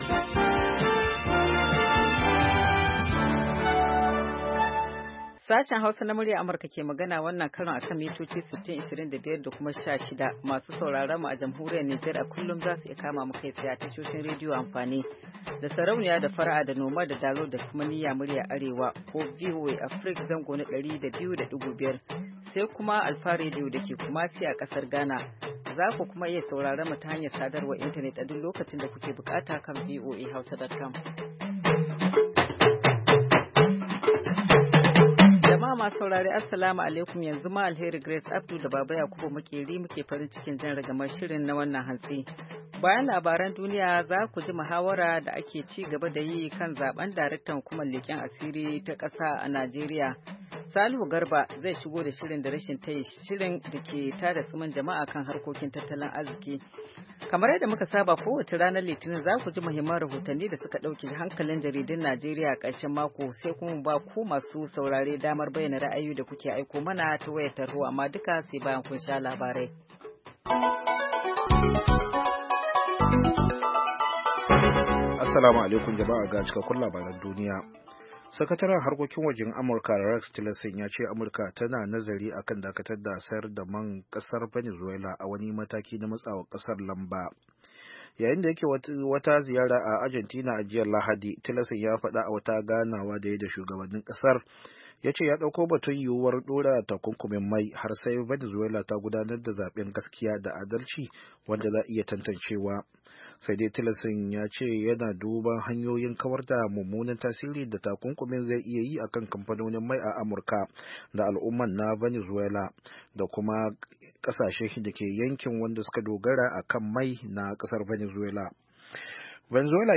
Mu kan komo da karfe 8 na safe agogon Najeriya da Nijar domin sake gabatar muku da labarai da hirarraki, da sharhin jaridu kama daga Najeriya zuwa Nijar har Ghana, da kuma ra’ayoyinku.